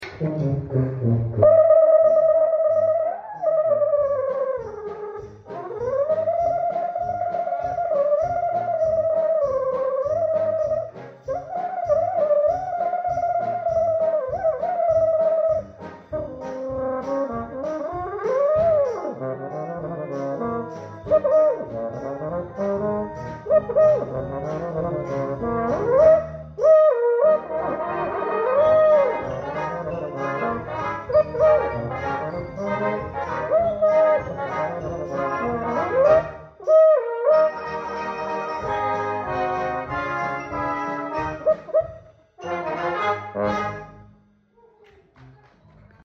Playing on a Adams E1 euphonium!